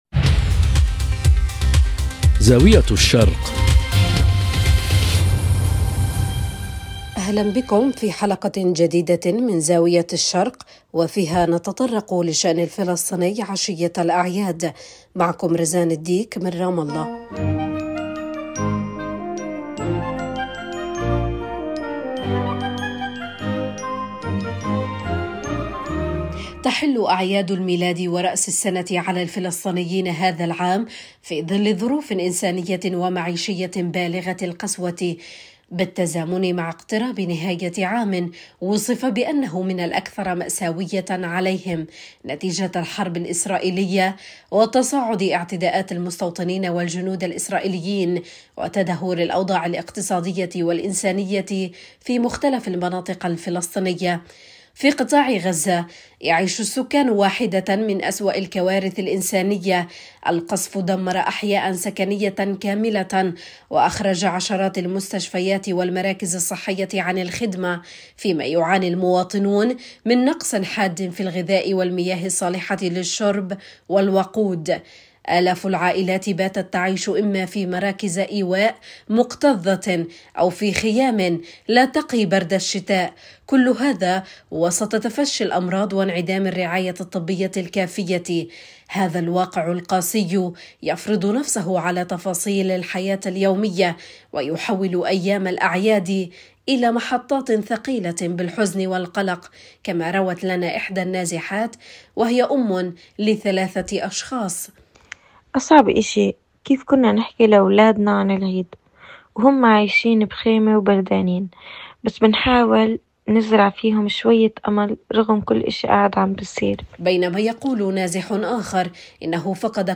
في قطاع غزة، حيث تتفاقم الكارثة الإنسانية، تروي أم نازحة وأم لثلاثة أطفال معاناتها اليومية في مراكز الإيواء، بينما يقول نازح آخر إنه فقد كل ما يملك وبات يواجه ظروفًا بالغة القسوة.